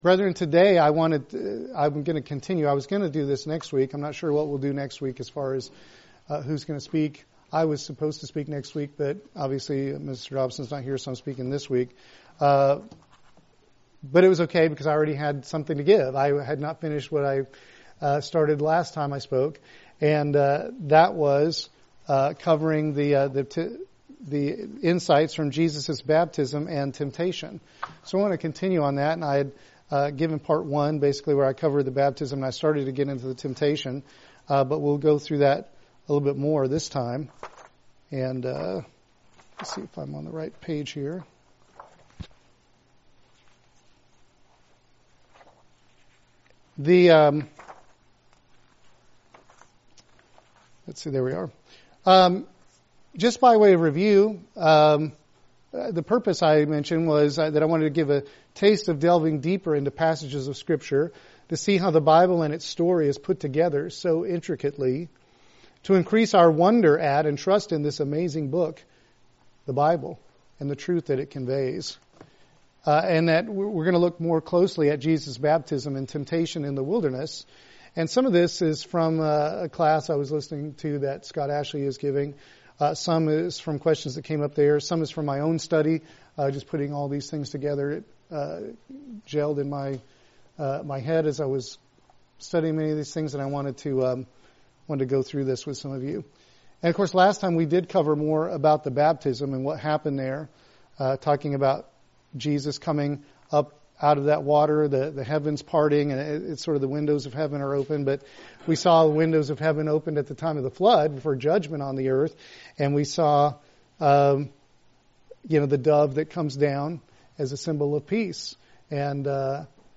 Given in Columbia - Fulton, MO